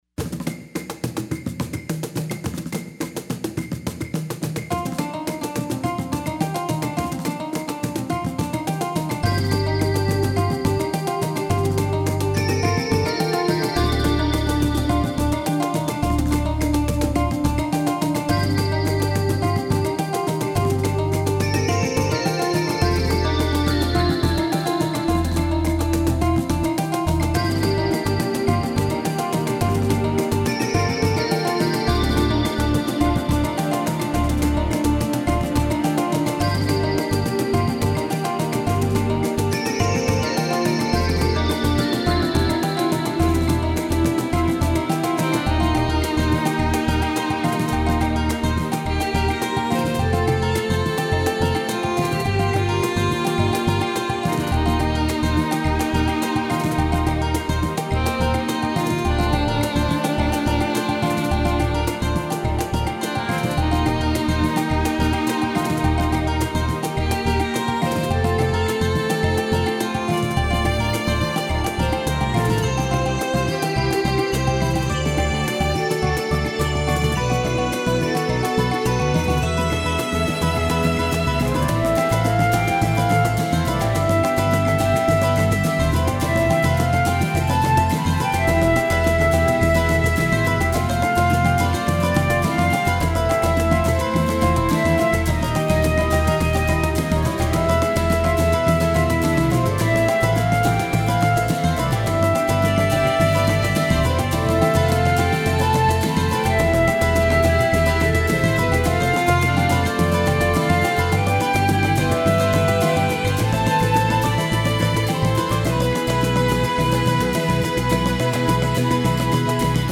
フリーBGM フィールド・ダンジョン ダンジョン
フェードアウト版のmp3を、こちらのページにて無料で配布しています。